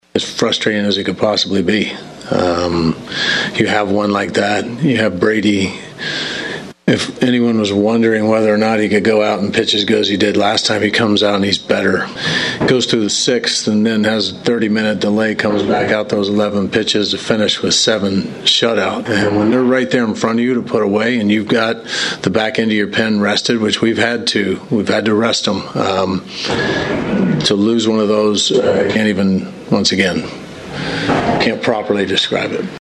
Royals manager Mike Matheny after the game.
5-23-mike-matheny.mp3